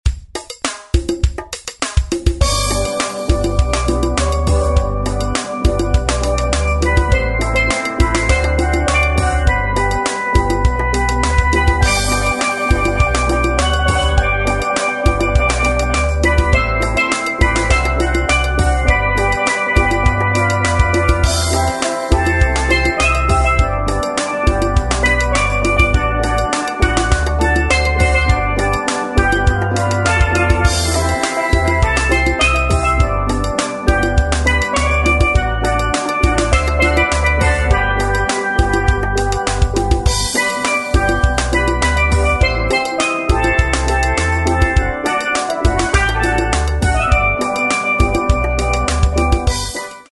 • Authentic Caribbean steel pan band